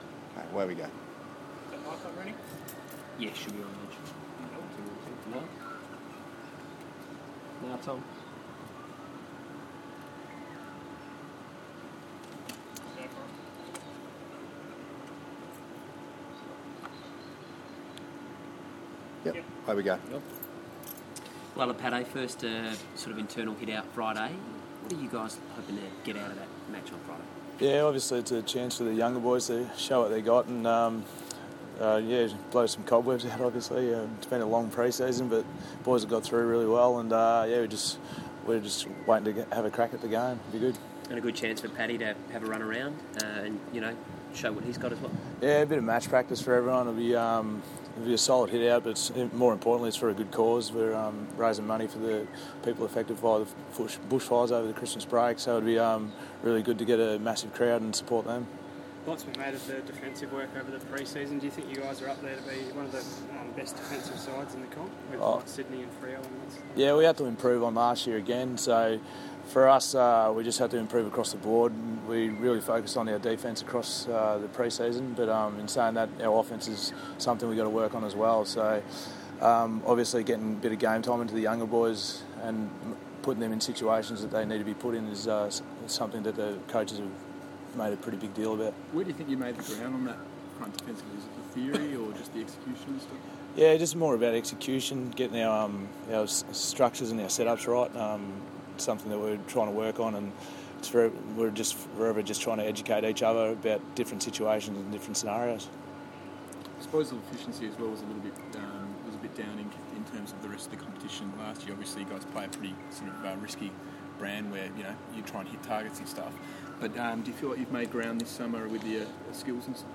Alipate Carlile press conference - Wednesday 25th of February 2015